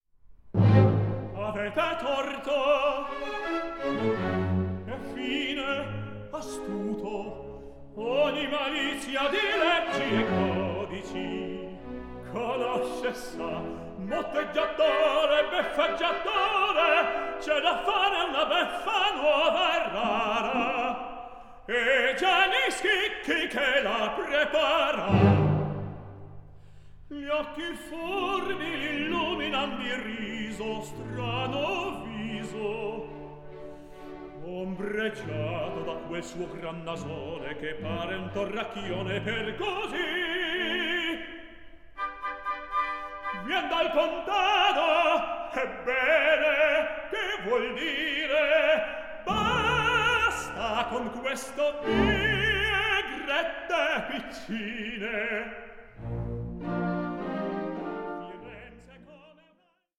HEART-WRENCHING VERISMO ARIAS